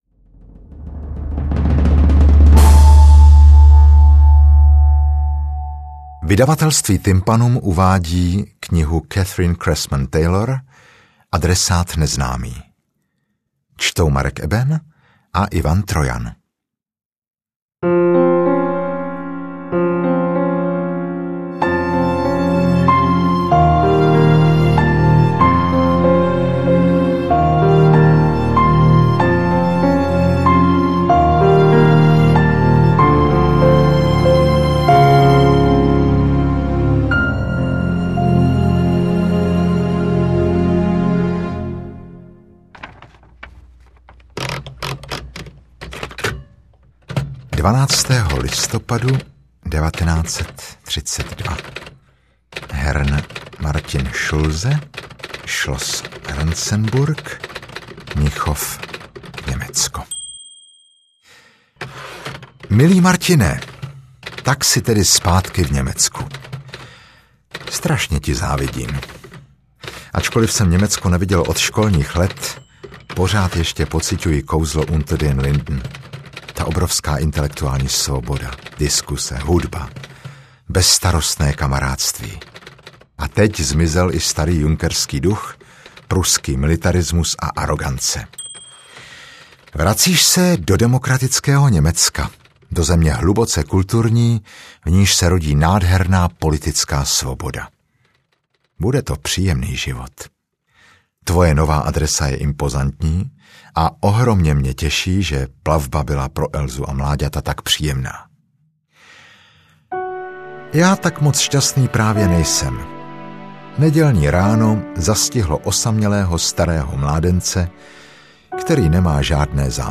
Interpreti:  Marek Eben, Ivan Trojan
AudioKniha ke stažení, 20 x mp3, délka 56 min., velikost 51,2 MB, česky